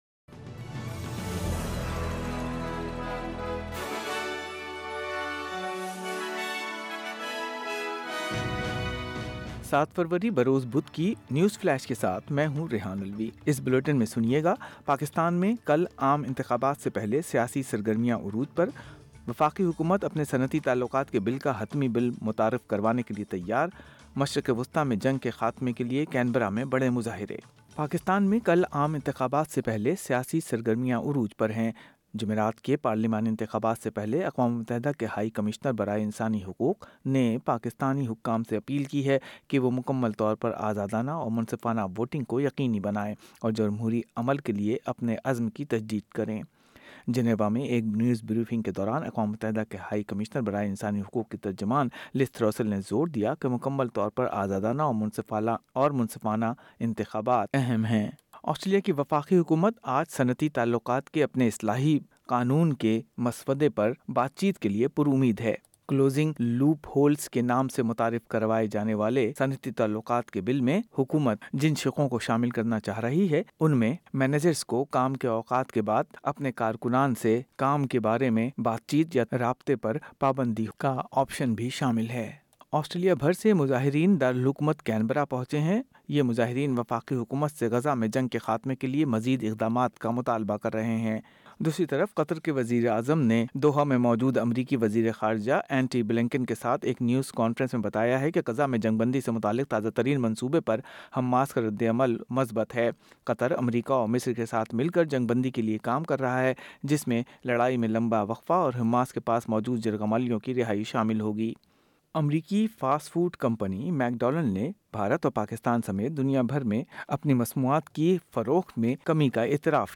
اردو نیوز فلیش ۔ بدھ 7 فروری 2024: حکومت ترمیمی صنعتی بل کی منظوری کے لئے پُرامید